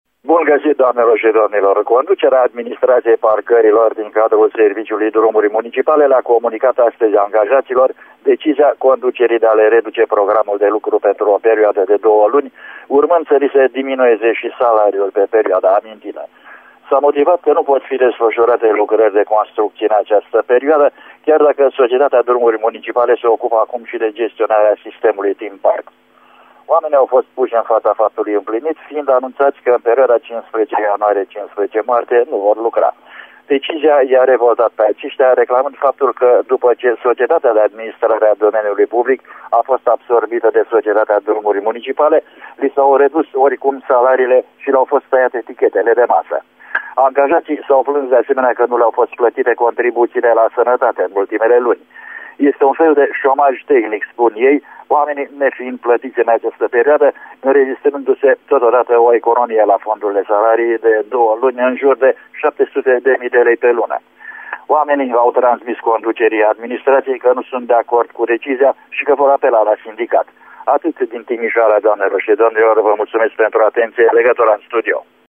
Cu mai multe informaţii, corespondentul nostru